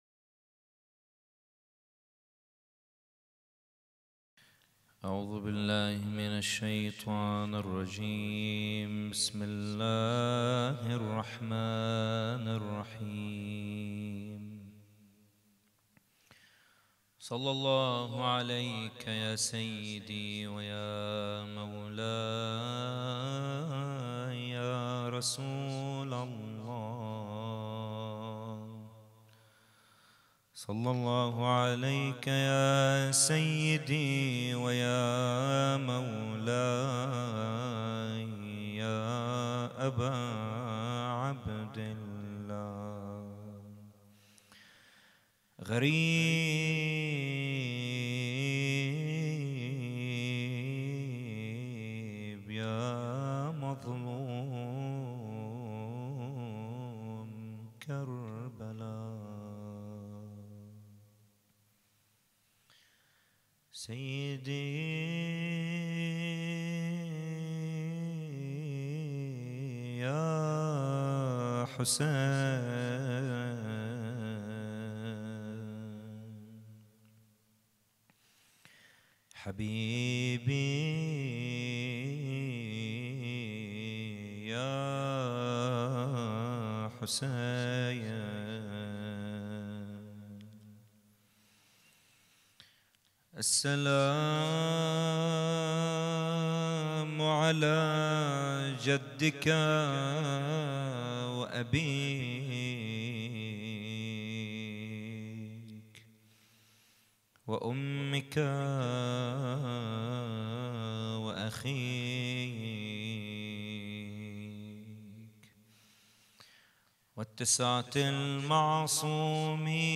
محاضرة
إحياء ليلة الثالث عشر من محرم 1442 ه.ق